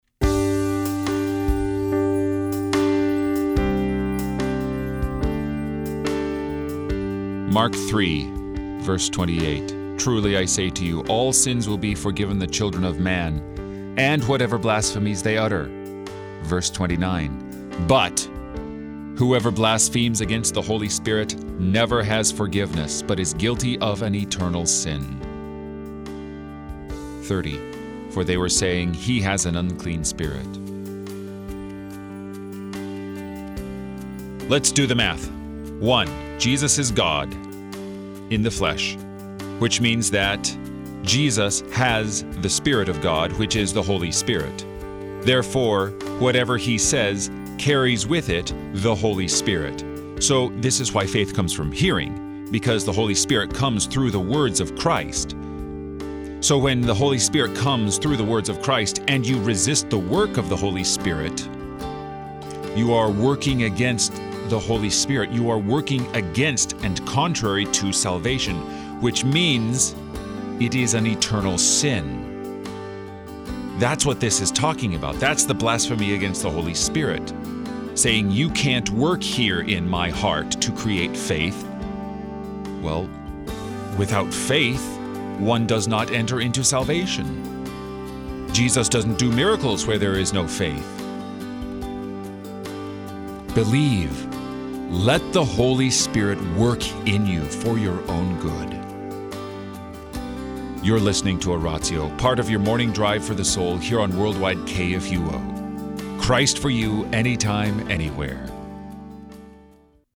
gives today's prayerful thought based on the day's Scripture readings.